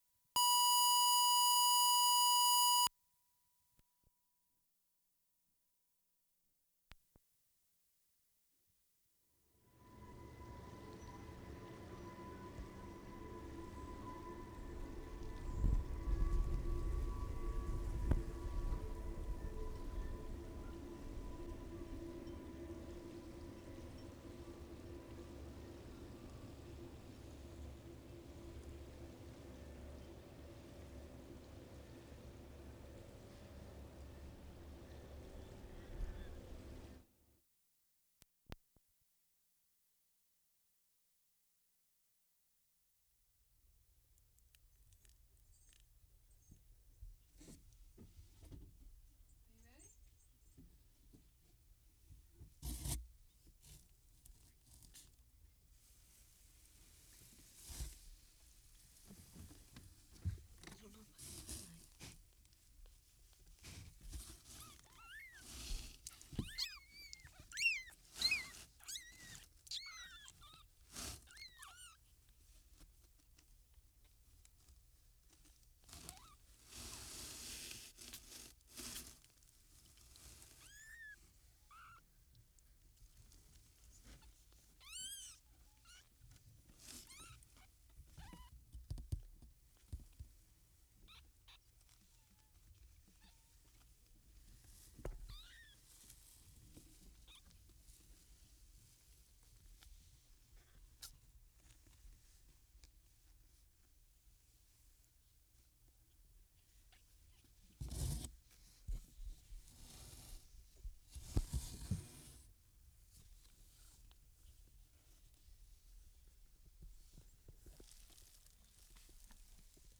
WORLD SOUNDSCAPE PROJECT TAPE LIBRARY
KITTENS 1'35"
4. Kittens close-up in very quiet space. Lots of microphone rumbling in first minute.
0'20" kittens squeaking,